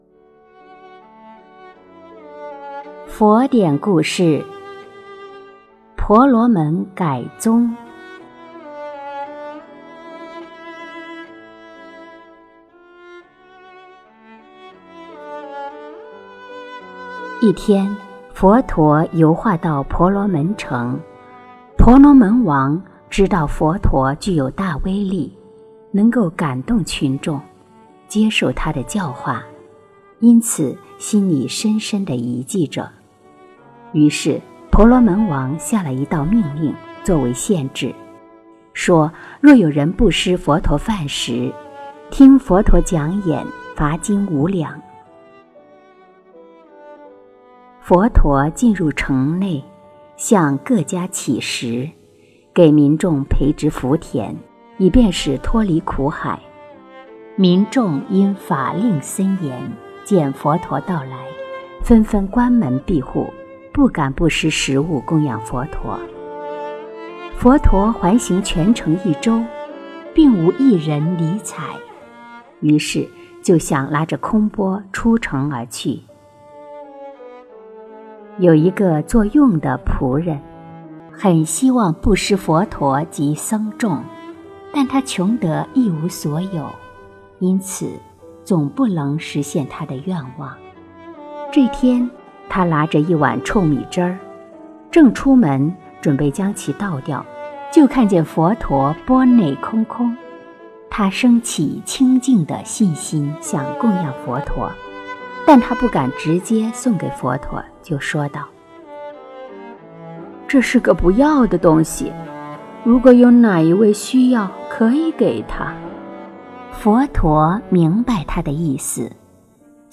诵经
佛音 诵经 佛教音乐 返回列表 上一篇： 狗转王女 下一篇： 狱中生白莲 相关文章 无量寿佛心咒(藏密调